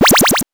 powerup_43.wav